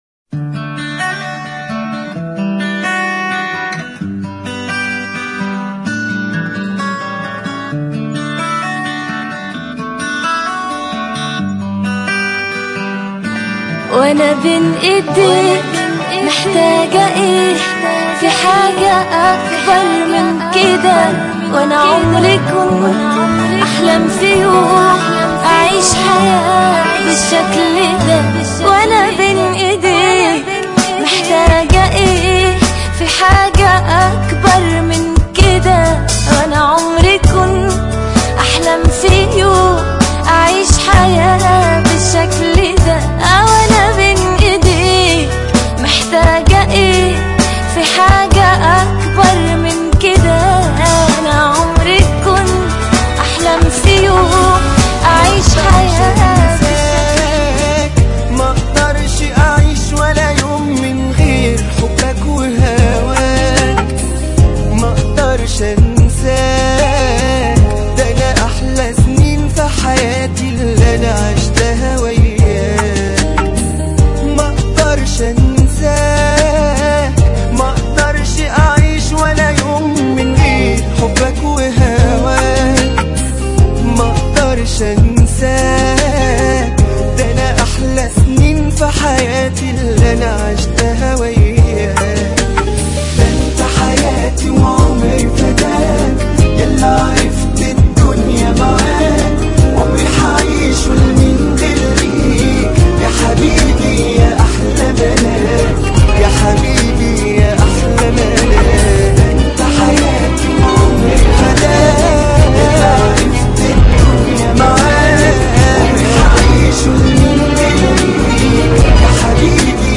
ميكس mp3